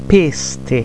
La pronuncia indicata qui è quella standard; va ricordato, però che la pronuncia aperta o chiusa delle e e delle o varia da regione a regione.
é = e chiusa; è e aperta
péste (le) noun P F __ pésta animal tracks
peste_ch.wav